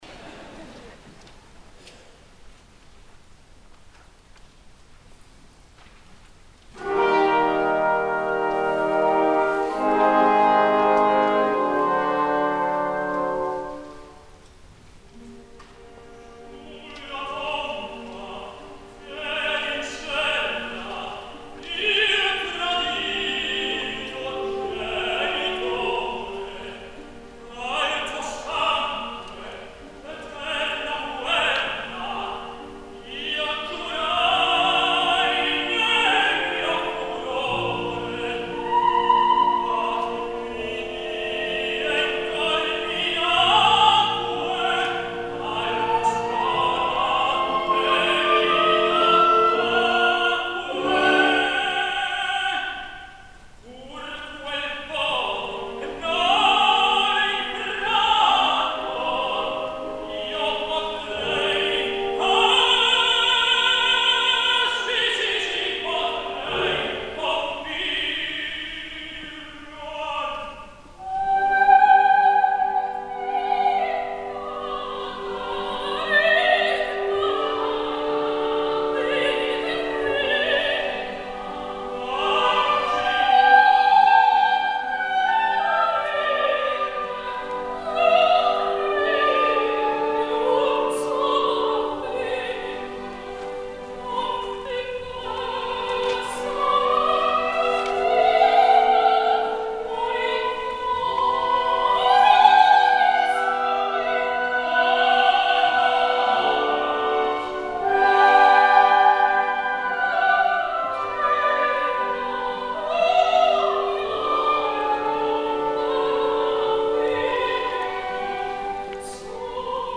El so de l’àudio hagués pogut ser millor.
Pretty Yende, soprano
Juan Diego Flórez, tenor
HET GELDERS ORKEST
Concertgebouw, Àmsterdam 19 de maig de 2015